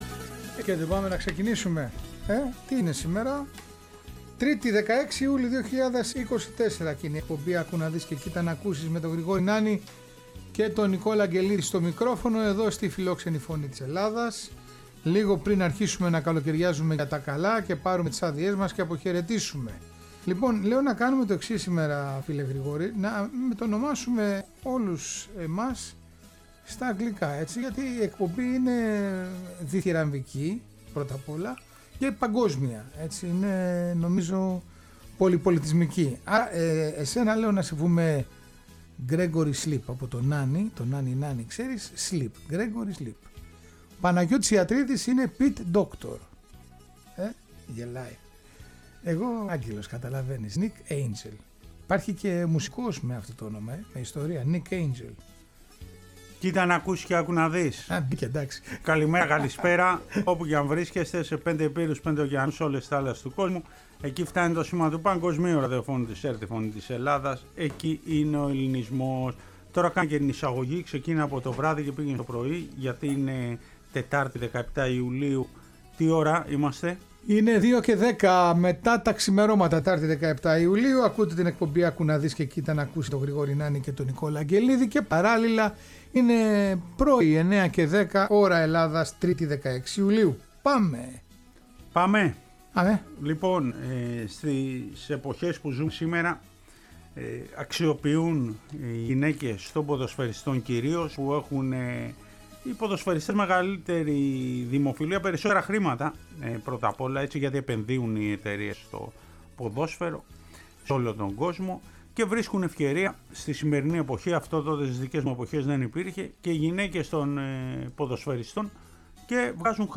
Τέλος μαθαίνουμε την ιστορία και ακούμε τον ύμνο του Παμπαιανικού.